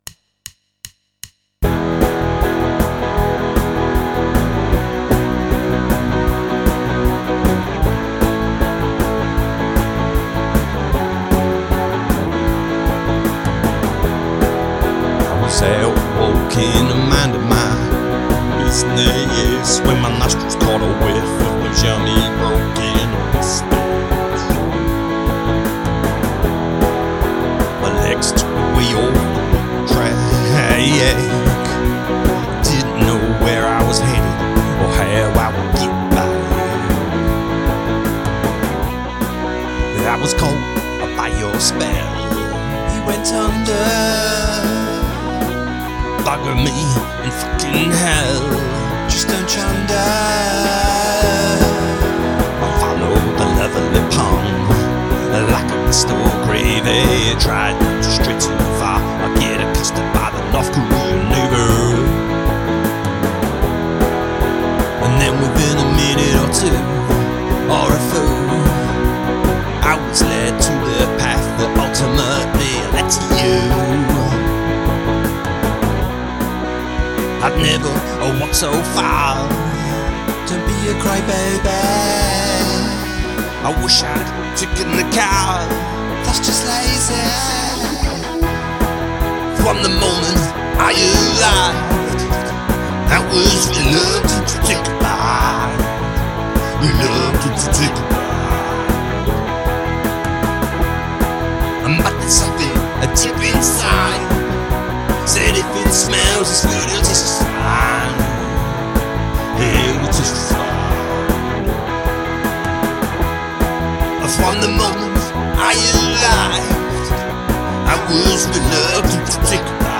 A make-up song, of course.
Some lovely vocals, verses and veins on this one!